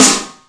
• Steel Snare Drum Sound B Key 52.wav
Royality free steel snare drum sample tuned to the B note. Loudest frequency: 4815Hz
steel-snare-drum-sound-b-key-52-XnR.wav